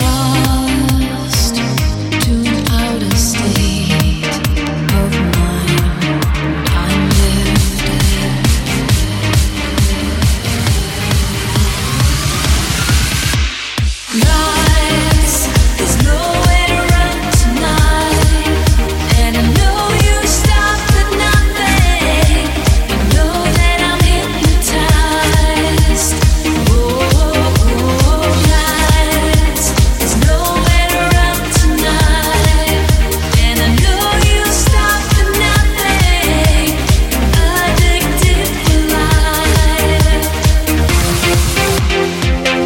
Genere: dance, edm, club, remix